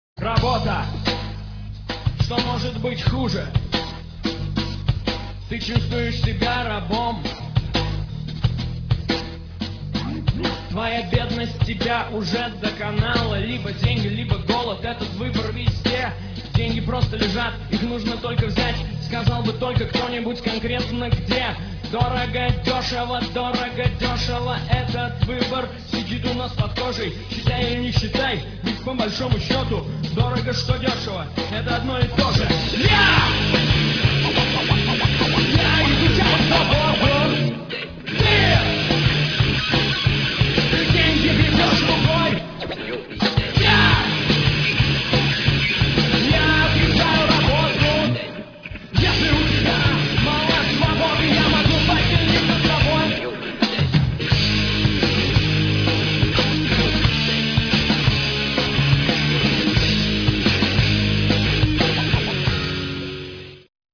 Шаболовка (1997)
фрагмент песни (1 мин. 04 сек.)